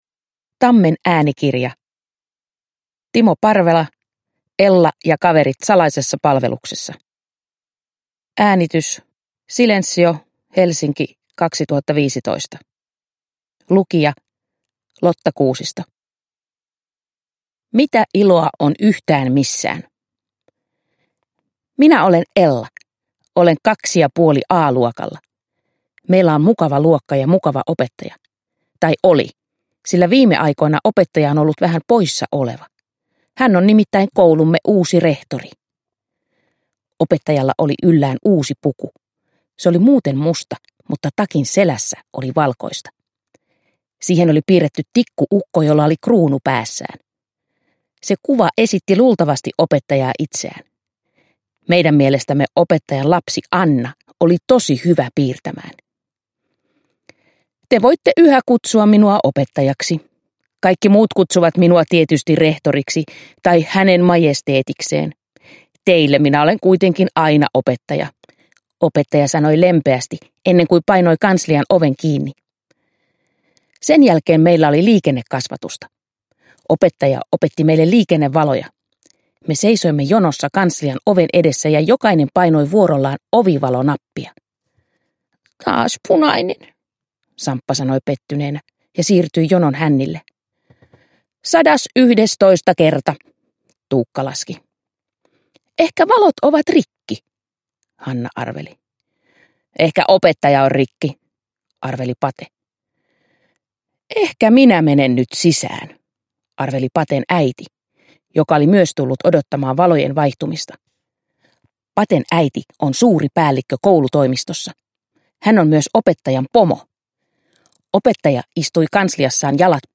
Ella ja kaverit salaisessa palveluksessa – Ljudbok